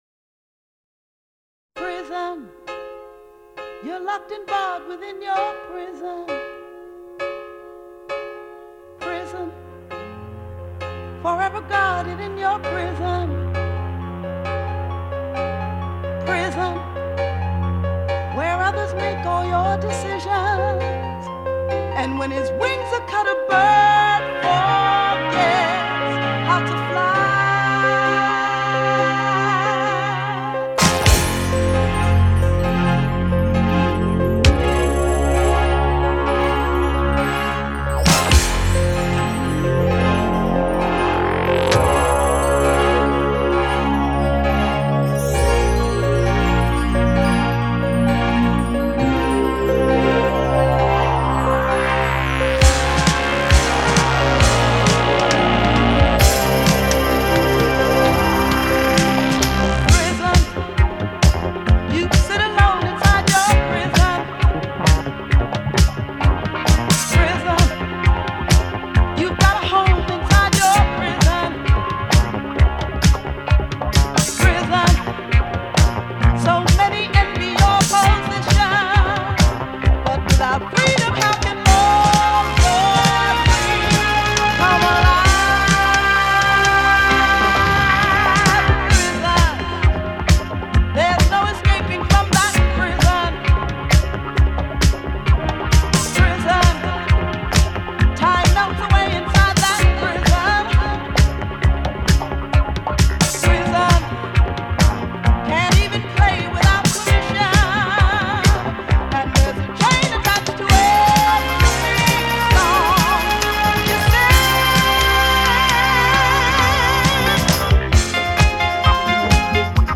Жанр: Electronic; Битрэйт